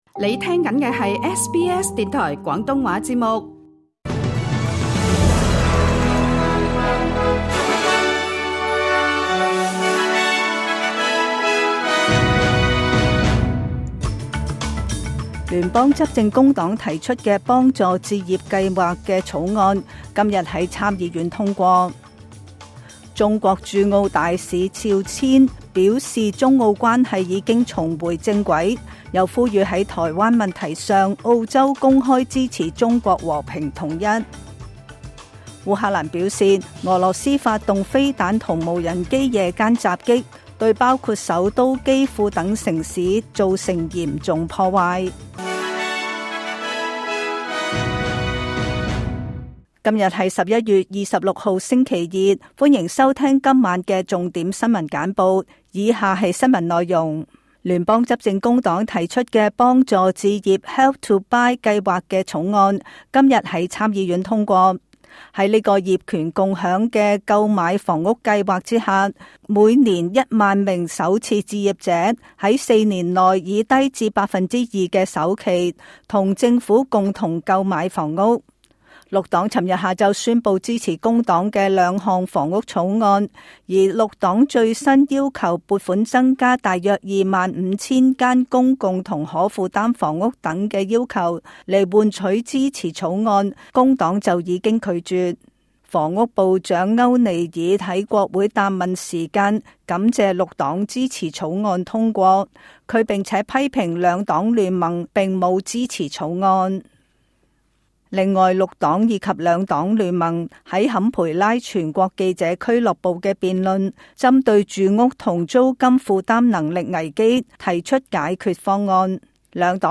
SBS 晚間新聞（2024 年 11 月 26 日）
請收聽本台為大家準備的每日重點新聞簡報。